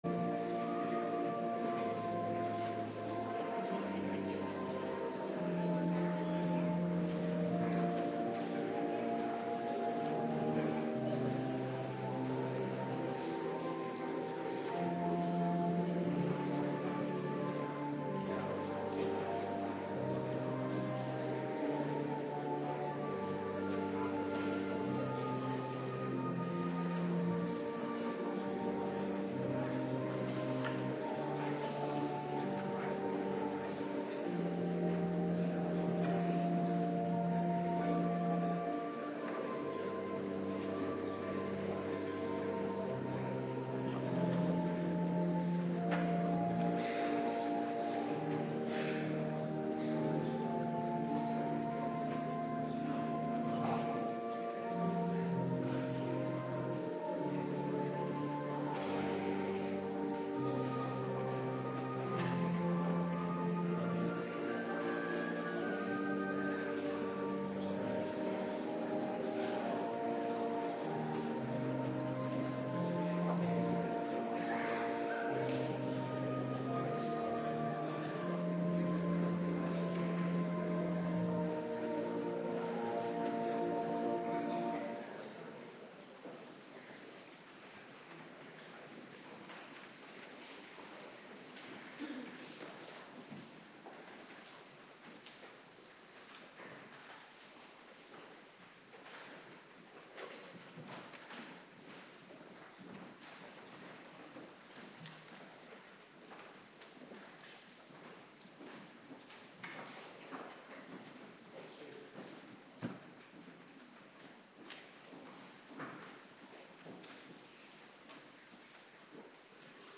Avonddienst Eerste Kerstdag
Locatie: Hervormde Gemeente Waarder